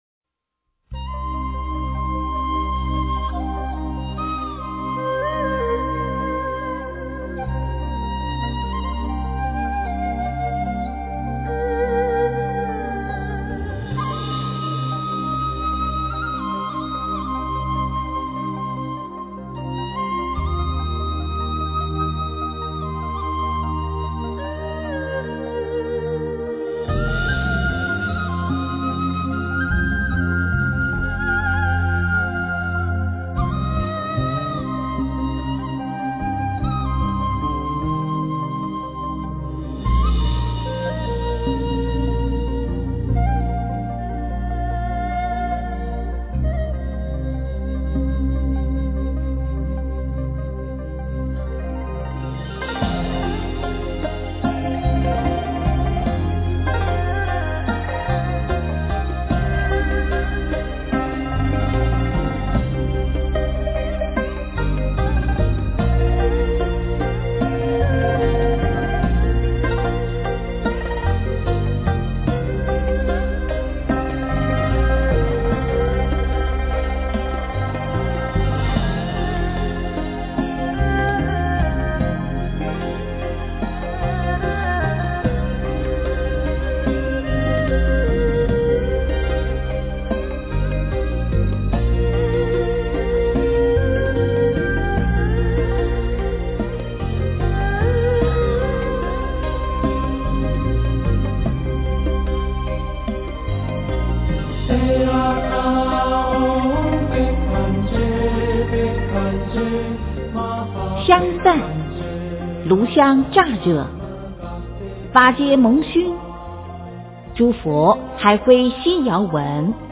药师经 诵经 药师经--佚名 点我： 标签: 佛音 诵经 佛教音乐 返回列表 上一篇： 佛说八大人觉经 下一篇： 自性歌-六祖坛经择句 相关文章 阿弥陀佛圣号--佛典艺术工作坊 阿弥陀佛圣号--佛典艺术工作坊...